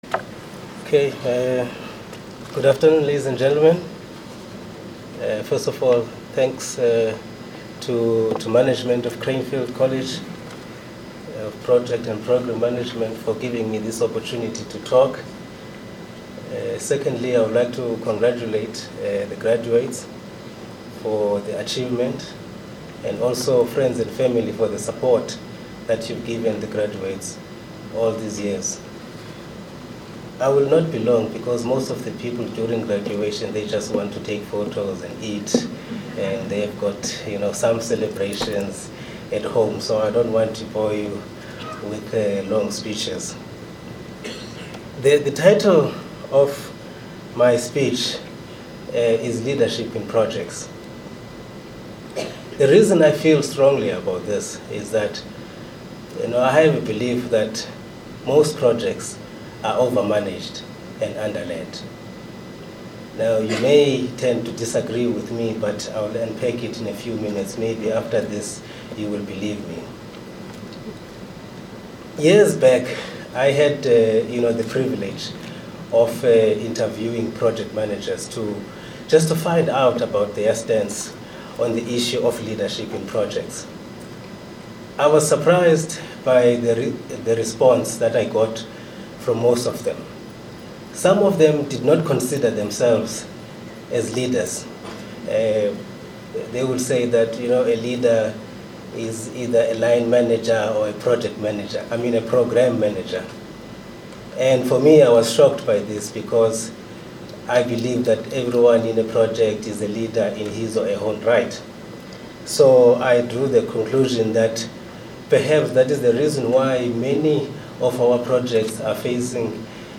Cranefield Graduation Speech - March 2013.mp3